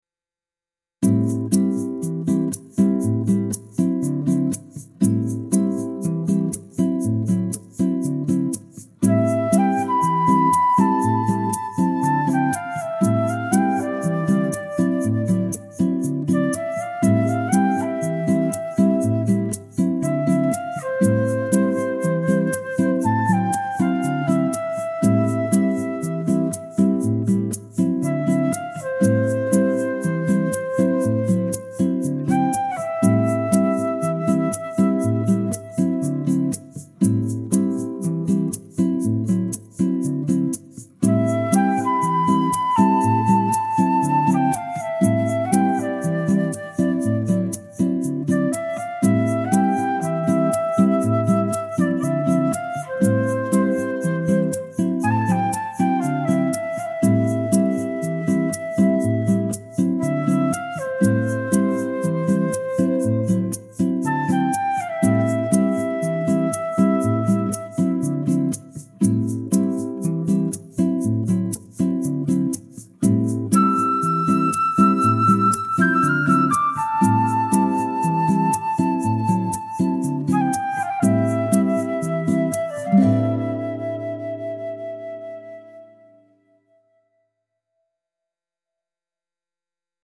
bossa nova inspired lounge music with nylon guitar and soft flute melody